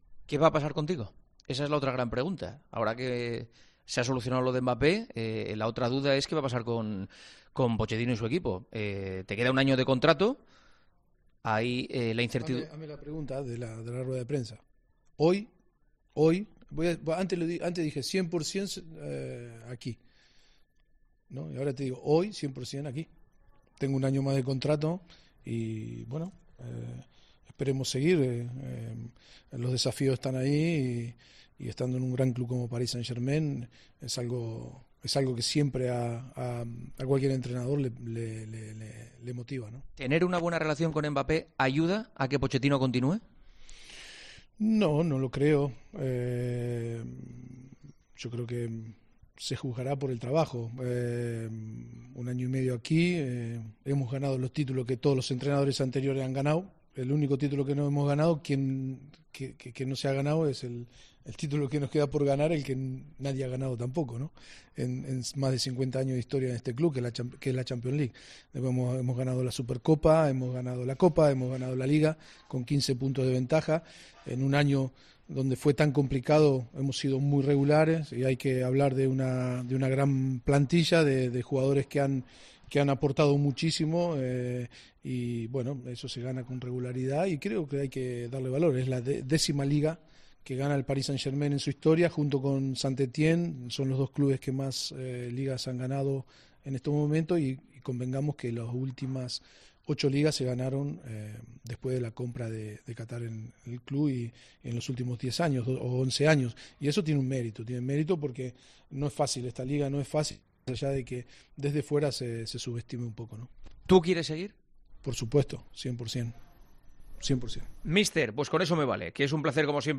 El entrenador del París Saint Germain ha concedido una entrevista a 'El Partidazo de COPE' a pocas horas de la final de la Champions League en la capital francesa